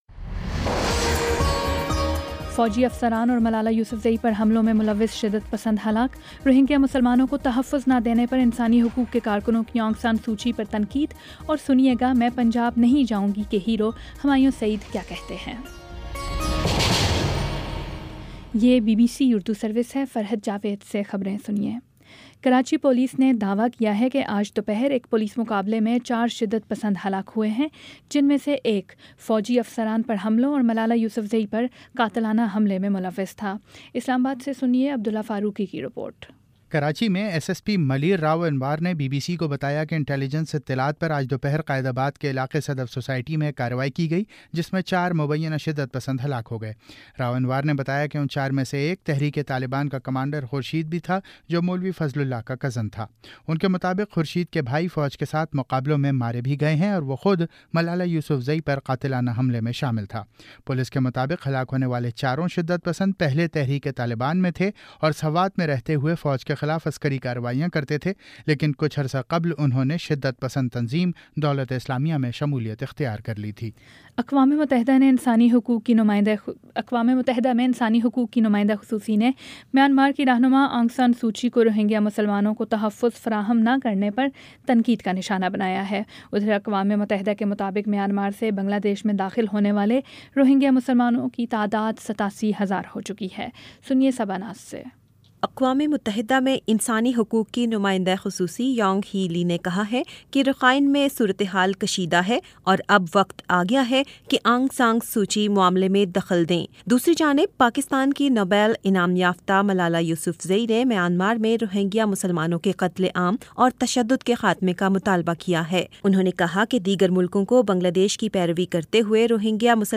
ستمبر 04 : شام پانچ بجے کا نیوز بُلیٹن